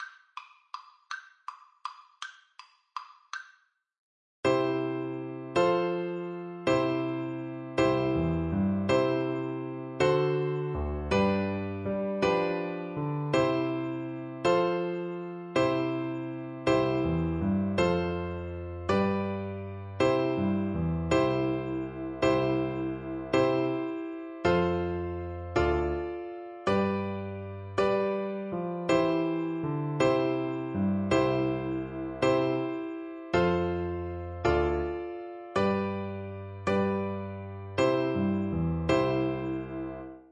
3/4 (View more 3/4 Music)
E5-E6
One in a bar . = c. 54
Traditional (View more Traditional Flute Music)
Scottish